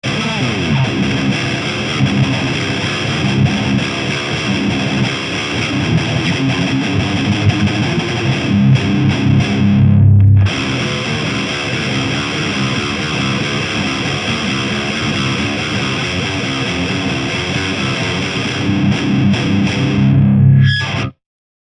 GROOVE TUBE GT-ECC83Sは低音が出るのとコンプ感が特徴です。
ゲインアップはしますが、ハイが多少出なくなります。
MTRはZOOM MRS-8を使いました。マイクはSM57とPG57
GAIN7 Bass8 Middle8 Treble7
LDDE2 ZW-44 WYLDE OVERDRIVE